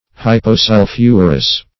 Hyposulphurous \Hy`po*sul"phur*ous\, a. [Pref. hypo- +